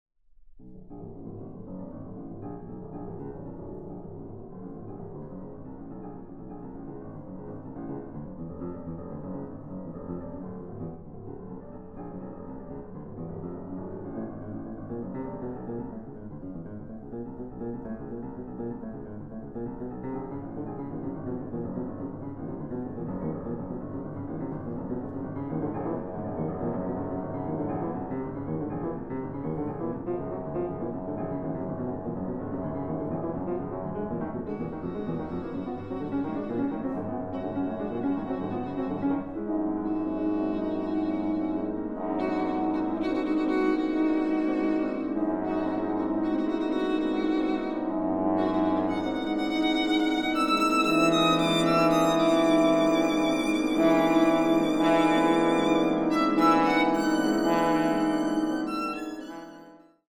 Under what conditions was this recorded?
Recording: Festeburgkirche Frankfurt, 2021